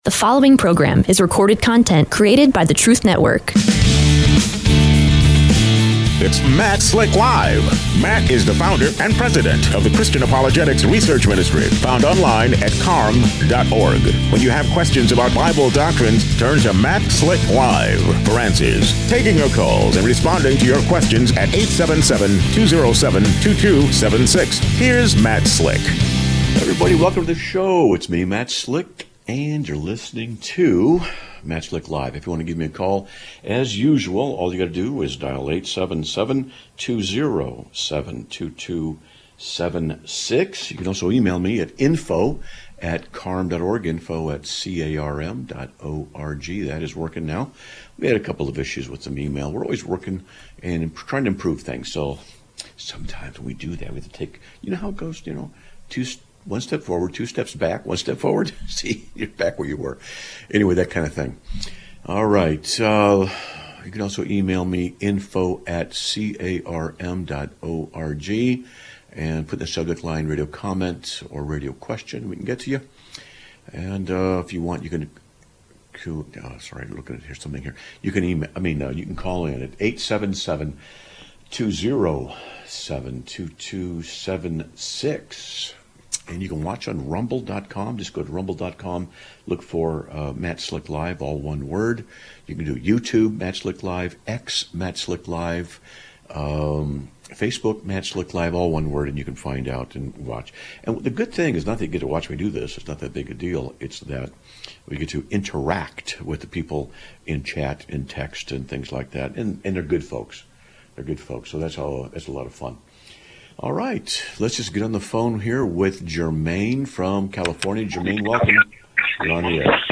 Live Broadcast of 01/12/2026